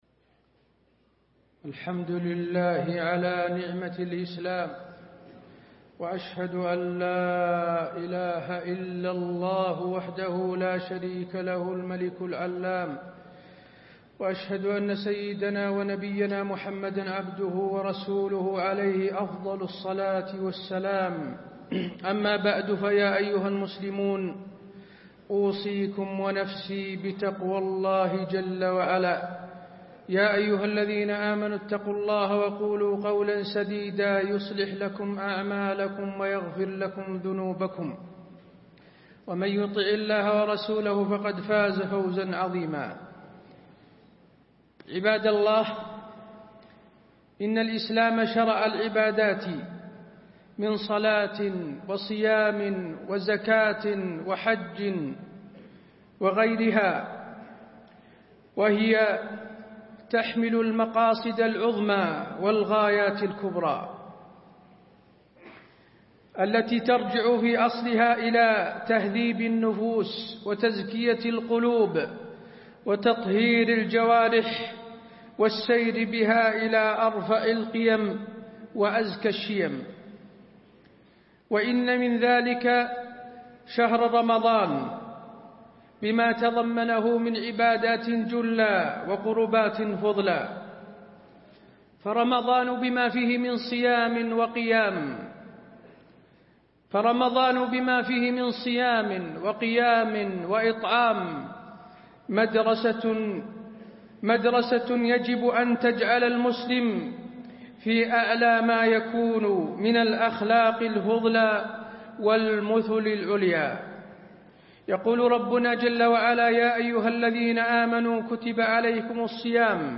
تاريخ النشر ١٧ رمضان ١٤٣٤ هـ المكان: المسجد النبوي الشيخ: فضيلة الشيخ د. حسين بن عبدالعزيز آل الشيخ فضيلة الشيخ د. حسين بن عبدالعزيز آل الشيخ رمضان وأخلاق الصائم